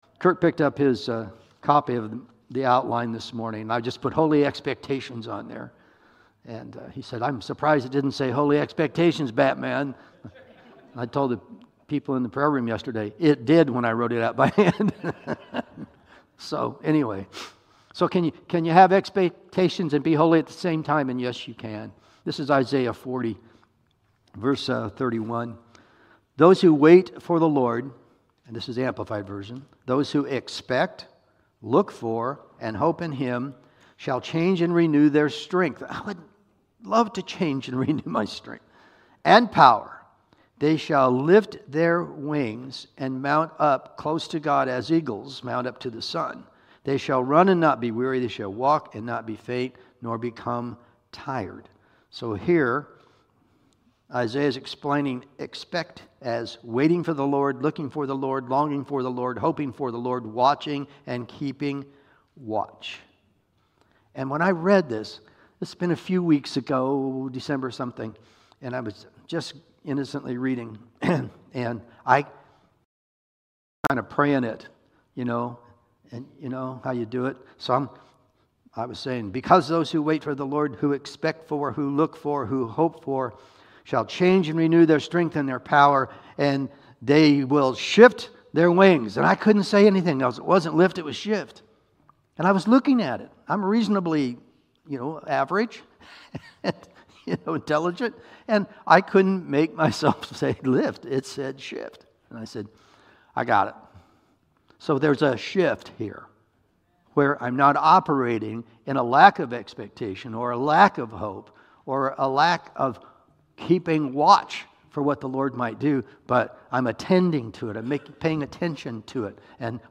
Service Type: Sunday Morning Sermon